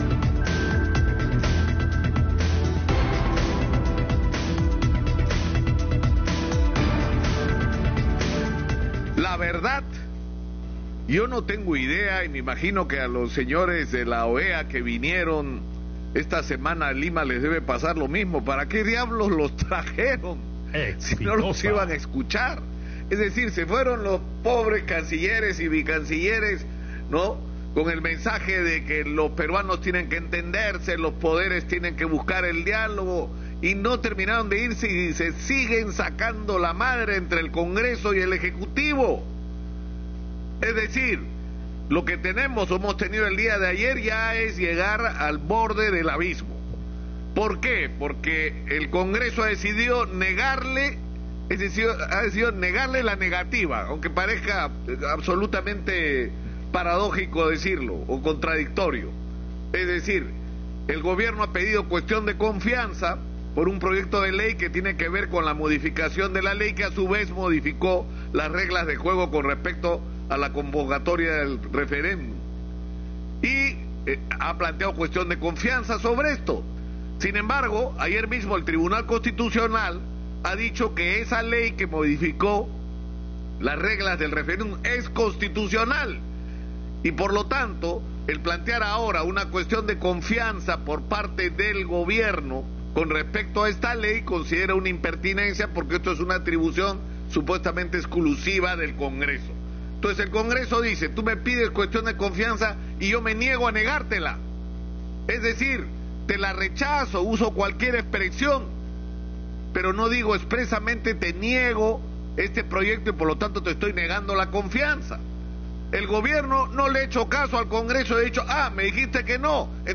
Comentarios de Nicolás Lúcar
por Radio Exitosa - Lima